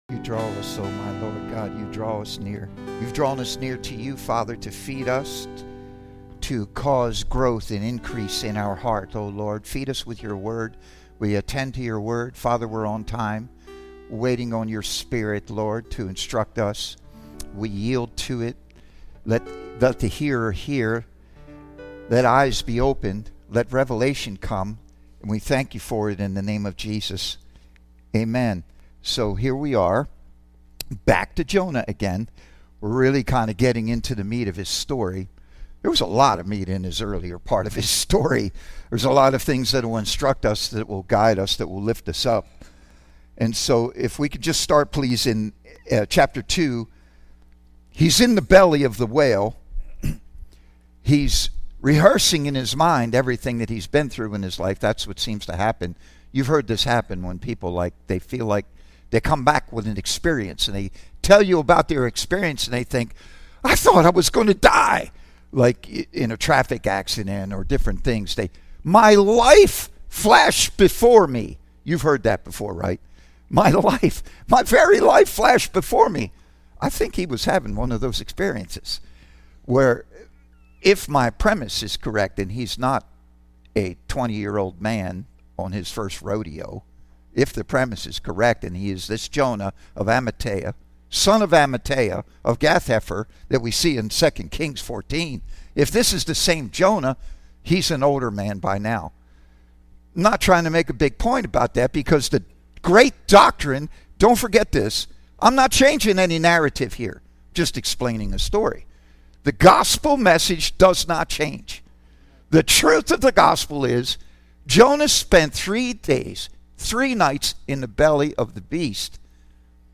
Teaching Service